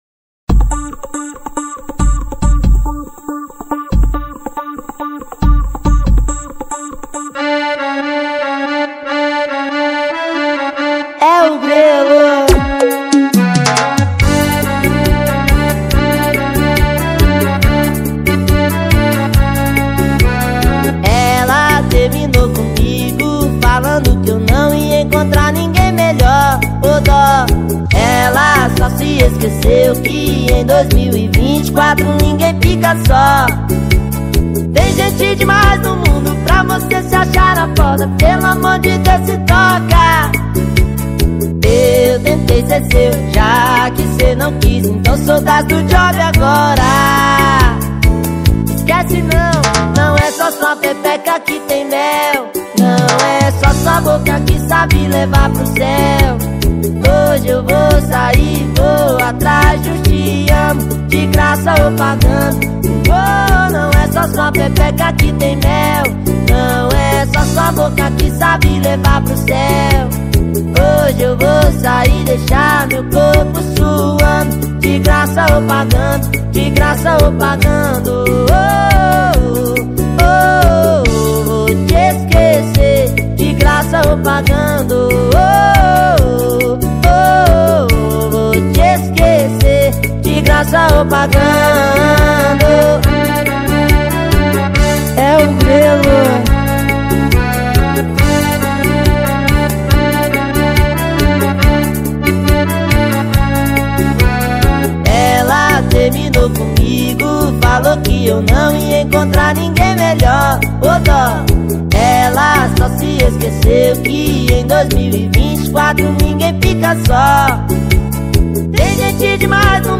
2024-10-09 04:37:29 Gênero: Forró Views